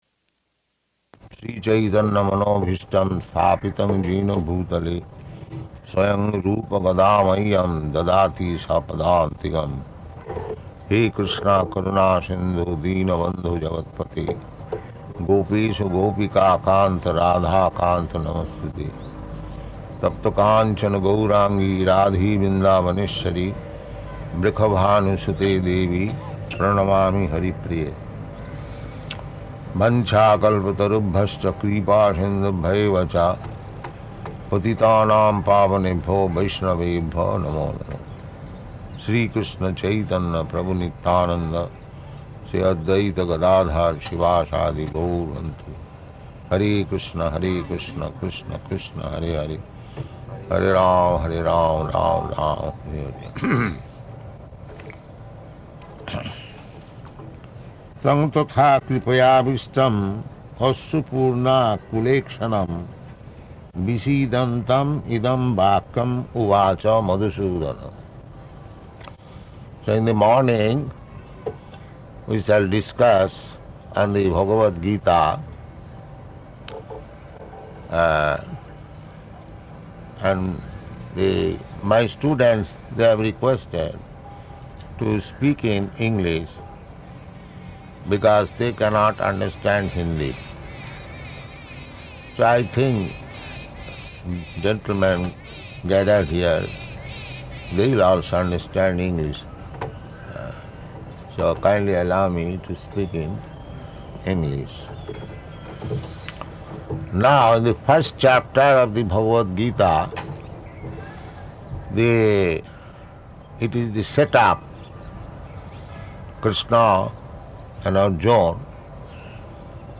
Location: Ahmedabad